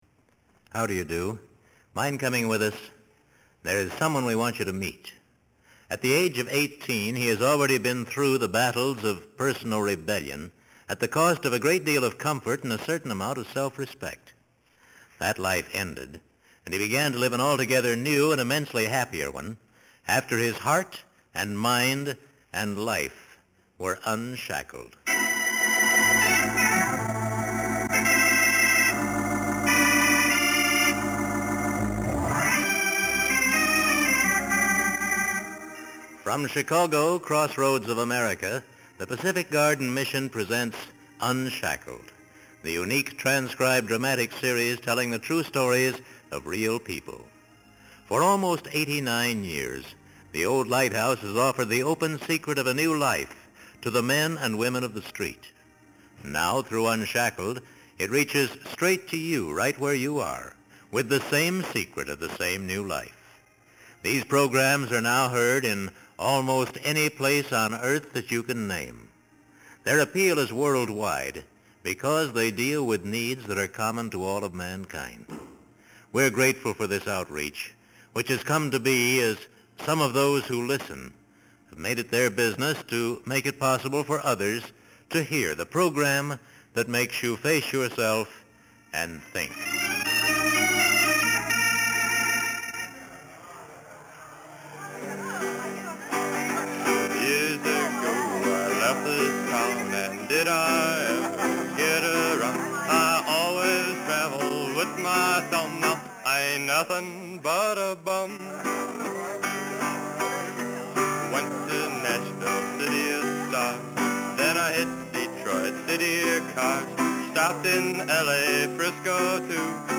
This was broadcast in 1966 when he had be a Christian for one year.